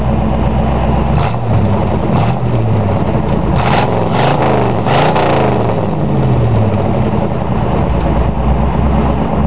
With no converter to pre-quiet things down and teeny weeny resonators, the sound is something extraordinary. Now that it has had a few miles to mellow out, it ranges from a mellow bark at slow cruising speeds to a roar at part-throttle acceleration to Total Armageddon at WOT (the window crank handles will rattle with the accelerator to the floor!).
Merging onto a highway at 70 kph